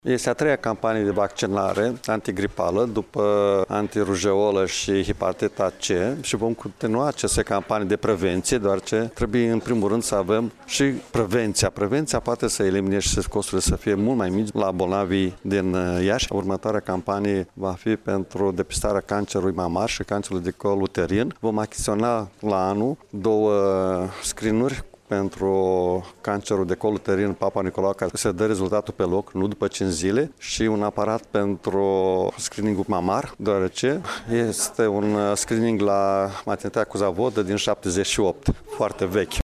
Aceasta este cea de-a treia campanie de vaccinare care se desfăşoară în judeţul Iaşi, a precizat preşedintele Consiliului Judeţean, Maricel Popa: